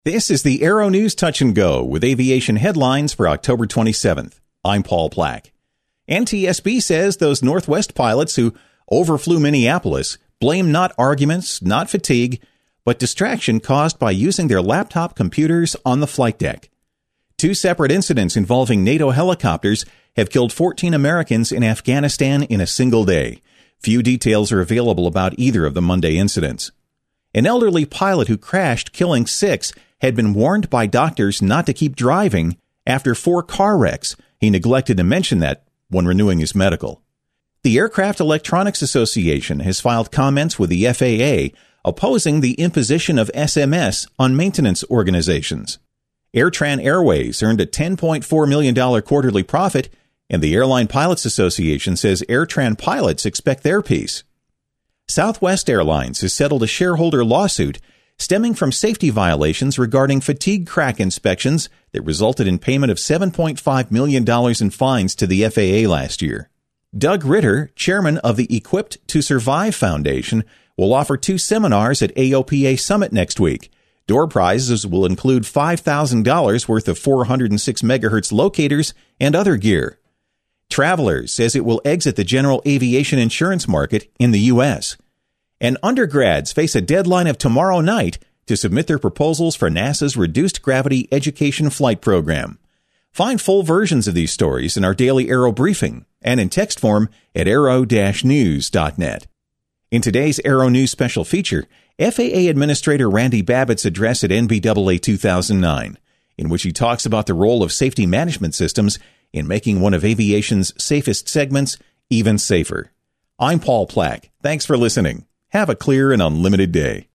FAA Boss Randy Babbitt Addresses NBAA Opening Session
FAA Administrator Randy Babbitt tells attendees at NBAA 2009 that Safety Management Systems and professionalism can make corporate aviation, one of aviation's safest segments, even safer.